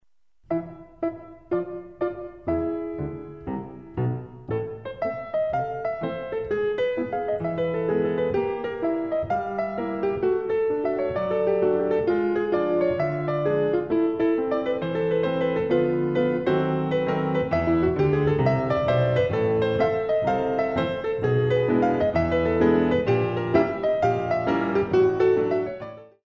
Compositions for Ballet Class
Some very Classical plus some jazz - ragtime rhythms
The CD is beautifully recorded on a Steinway piano.
Degages